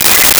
Megaphone Feedback 01
Megaphone Feedback 01.wav